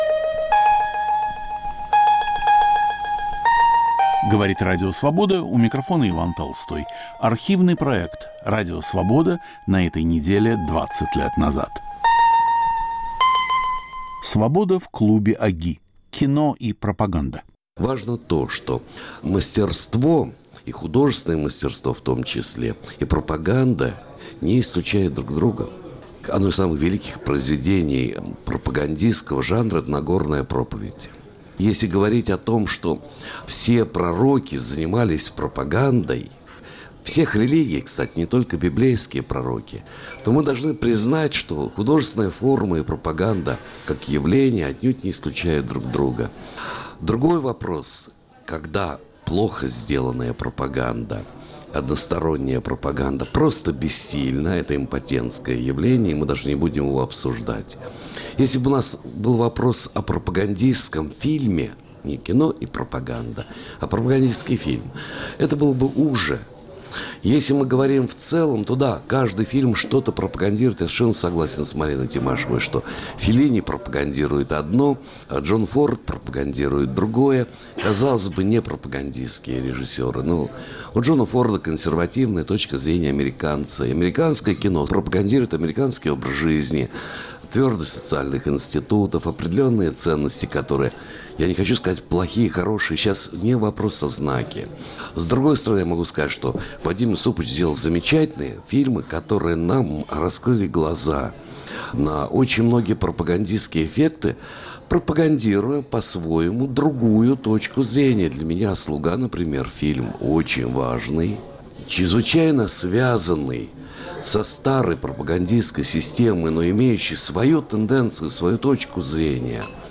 "Свобода" в клубе ОГИ. Кино и пропаганда